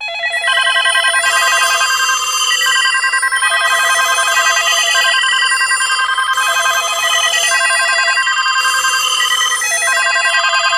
Index of /90_sSampleCDs/E-MU Producer Series Vol. 3 – Hollywood Sound Effects/Human & Animal/Phone Collage
PHONE COL00R.wav